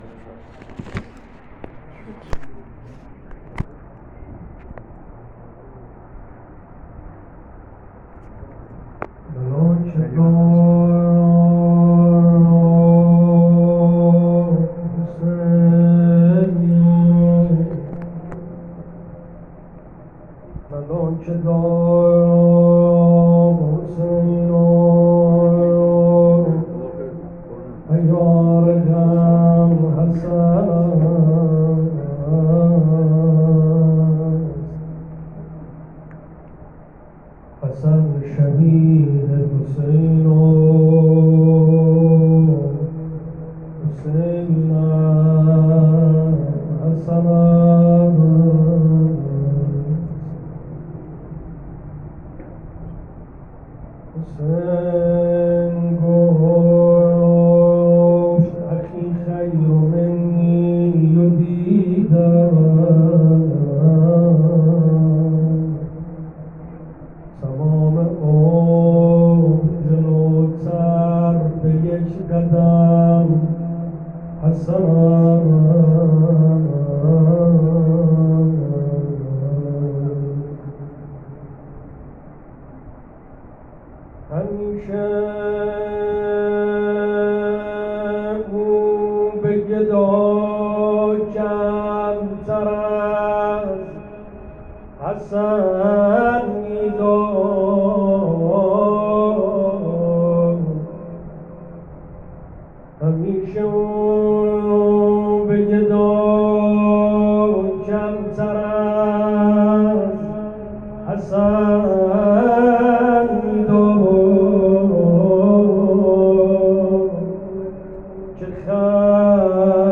روضه عبدالله بن الحسن